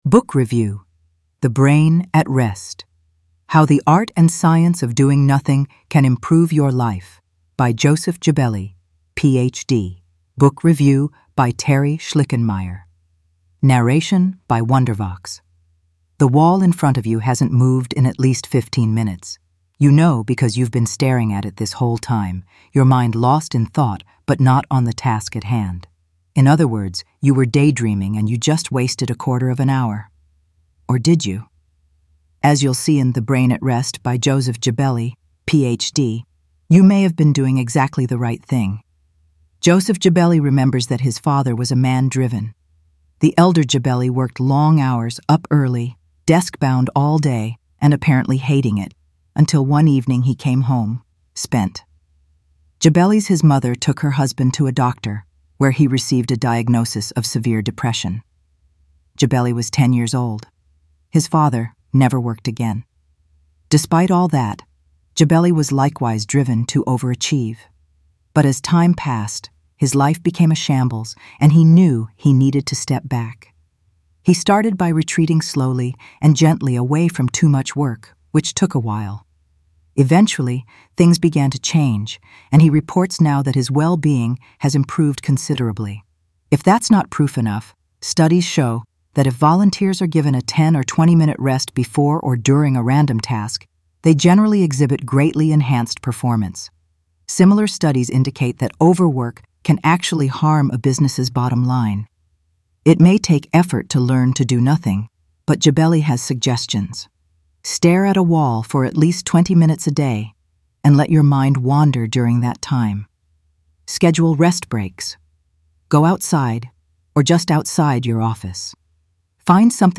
Narration provided by Wondervox.